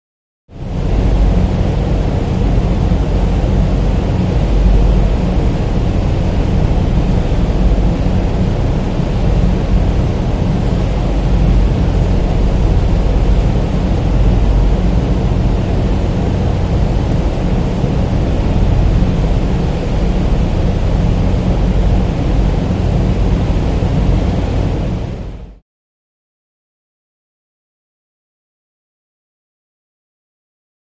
Hydroelectric Background; Low Frequency Rumble With Large Industrial Fan Noise, Close Perspective.